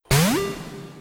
PokeballOpen.wav